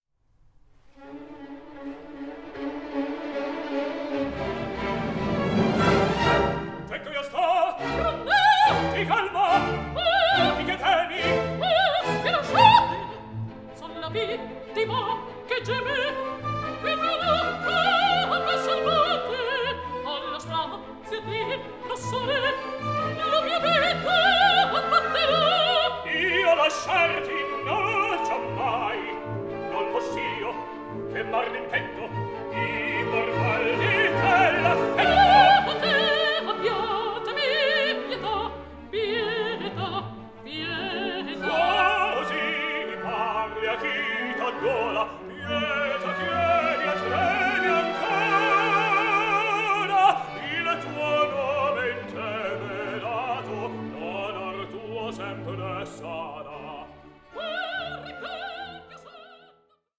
resulting in a performance that is lively and balanced.